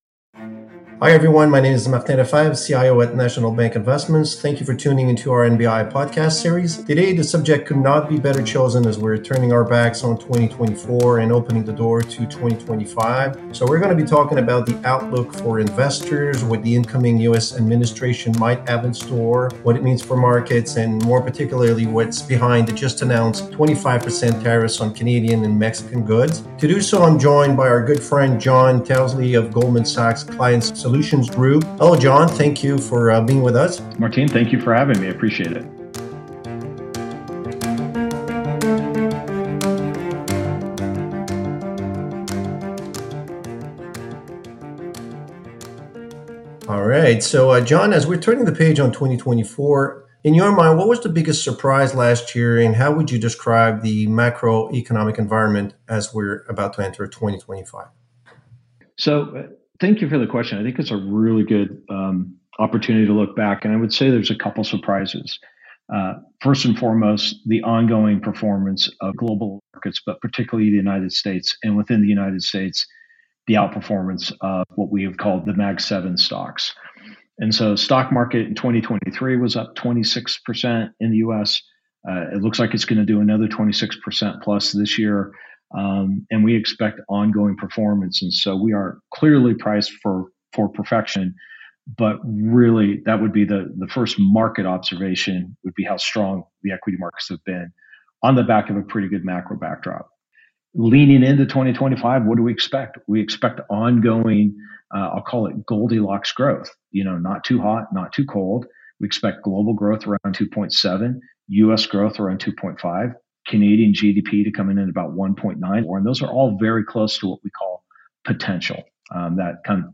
host
guest